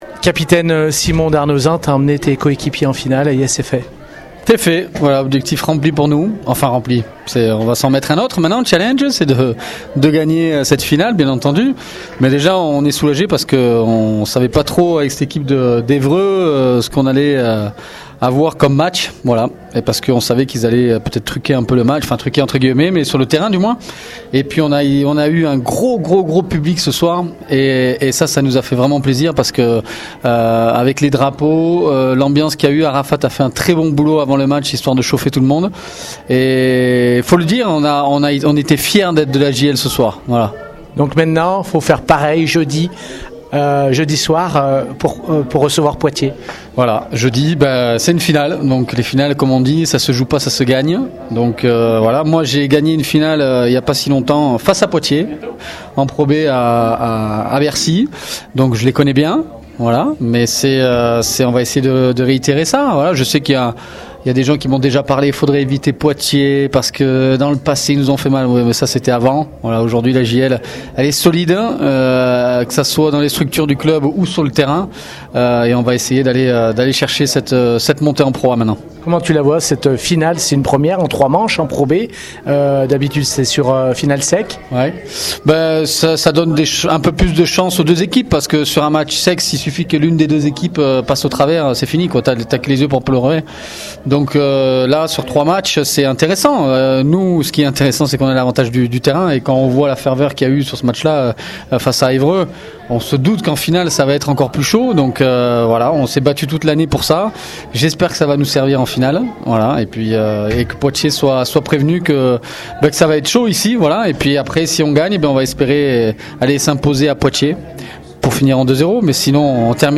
Retrouvez d’autres interviews tous les jours jusqu’au 1er match de jeudi.